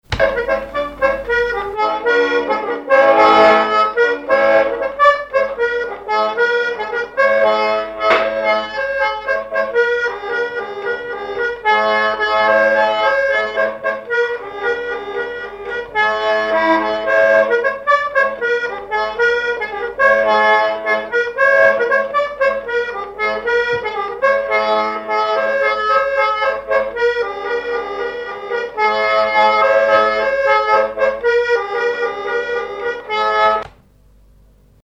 Sallertaine
Chants brefs - A danser
danse : gigouillette
accordéoniste
Pièce musicale inédite